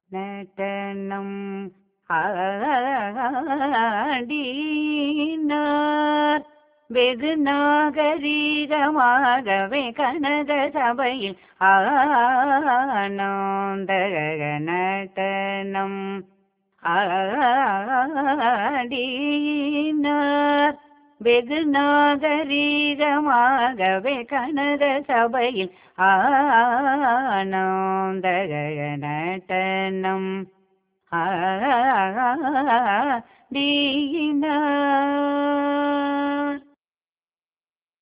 வசந்தா ராகக் கீர்த்தனை இது. கண்டசாபு தாளத்தில் அமைந்திருக்கிறது.